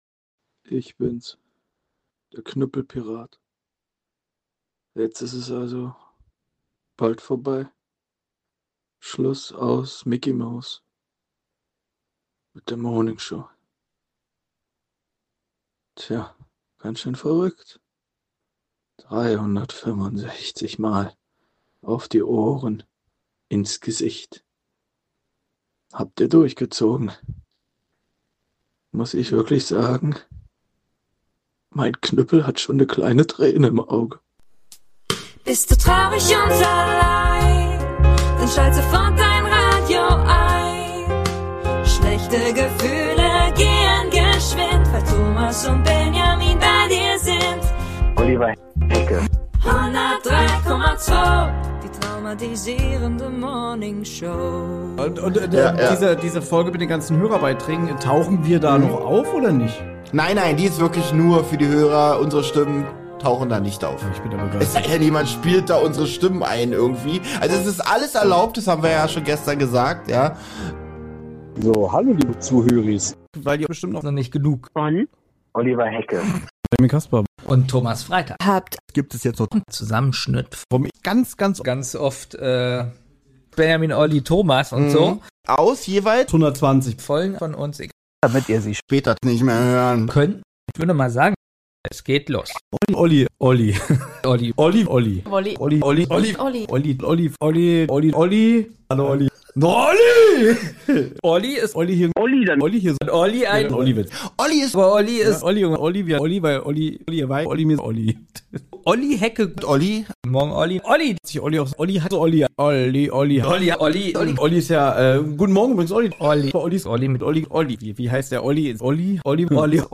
Ganz kurz vor Schluß, kommt IHR nochmal zu Wort... Es folgen Grüße, Sprüche, Anekdoten, Philosophisches und etwas Musik...Und das Alles von Euch.